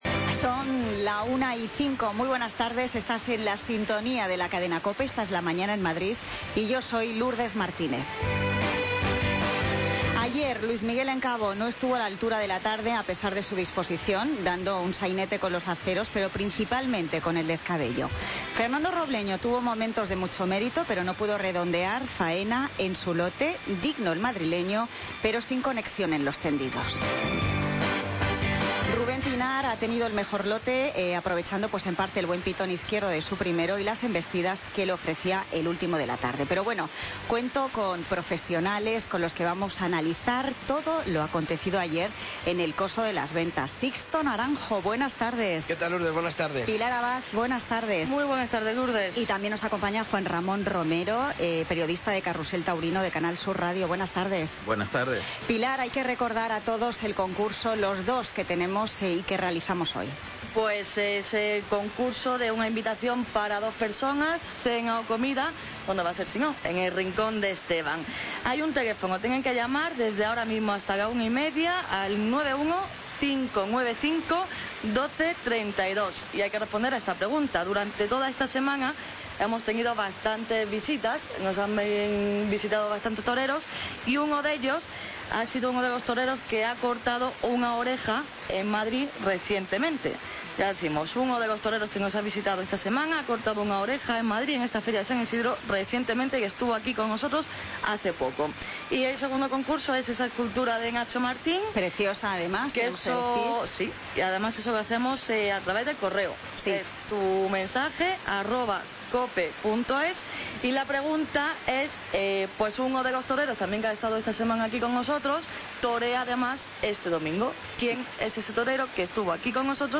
Tertulia Taurina Feria San Isidro COPE Madrid, viernes 3 de junio de 2016